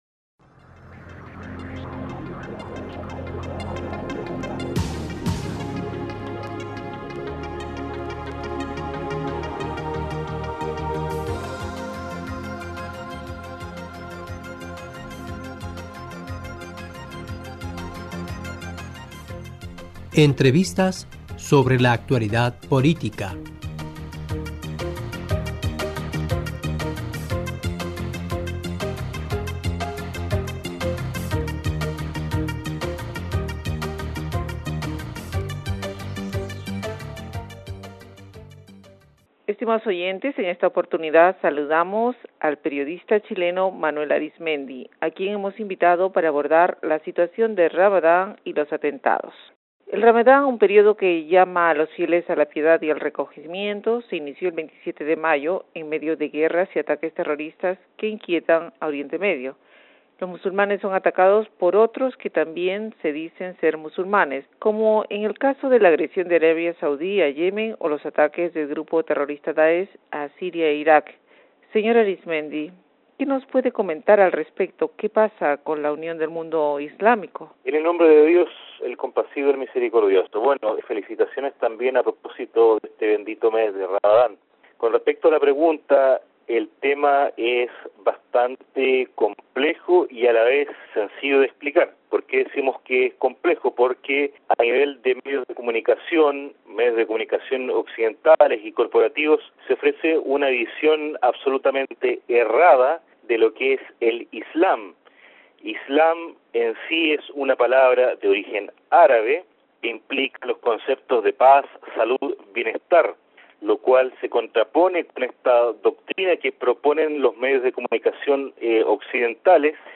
Estimados oyentes, en esta oportunidad saludamos al periodista chileno